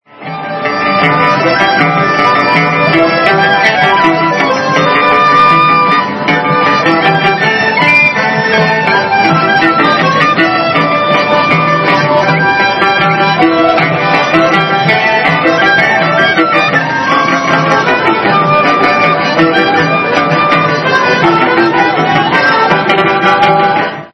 There are also musicians, some with a big audience and some just jamming for fun:Singer Bluegrass SortofClick here for a sample of their sound:
Park Musicians
Park-Musicians.mp3